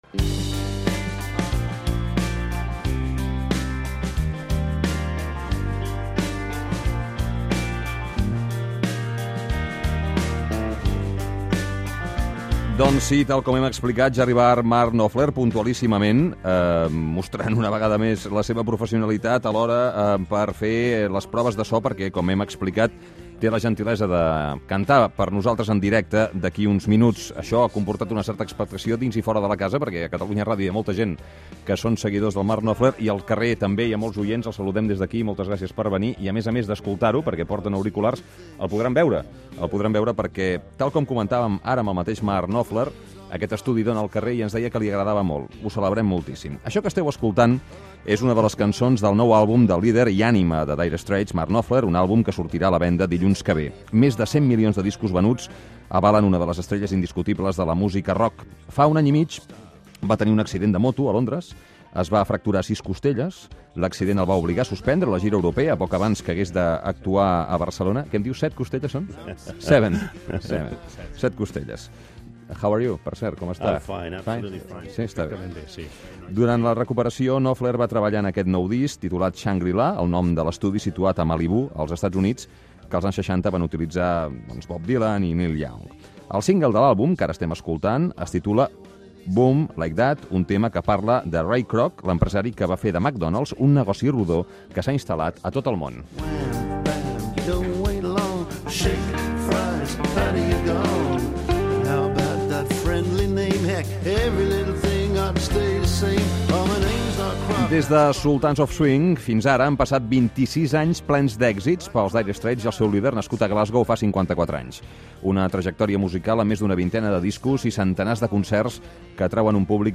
Entrevista al músic Mark Knopfler de Dire Straits que presenta disc, parla de la seva trajectòria i opinia sobre la Guerra d'Iraq